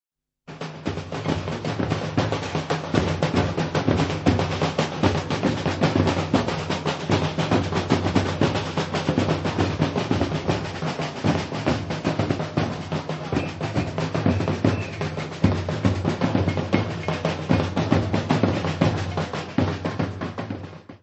Carnavals du Bresil : Rio, Recife, Bahia : live recording = Carnivals of Brazil
Área:  Tradições Nacionais
Ecole de Samba - Ranchos - Récife.